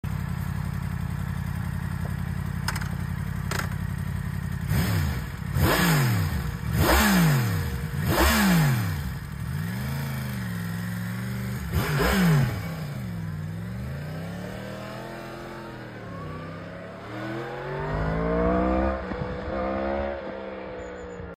MT 10 Worlds Fastest Hyper Naked sound effects free download
MT-10 Worlds Fastest Hyper Naked Launch💥💨